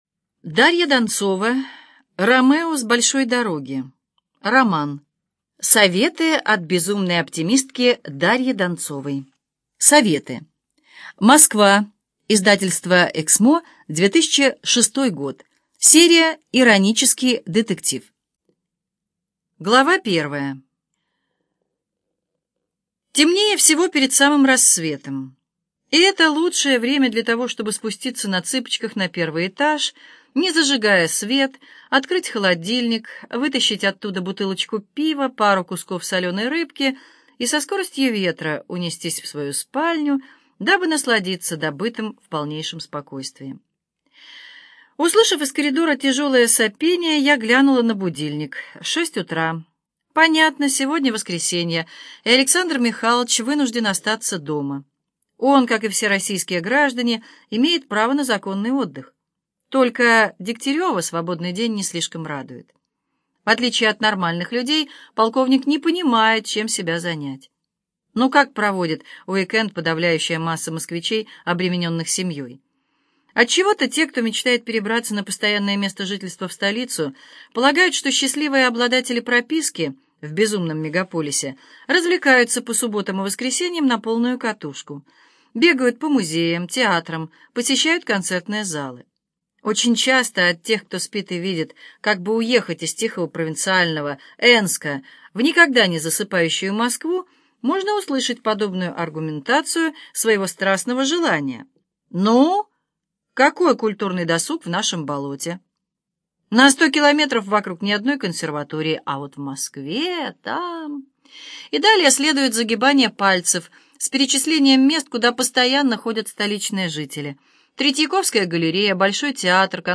Аудиокнига Ромео с большой дороги - купить, скачать и слушать онлайн | КнигоПоиск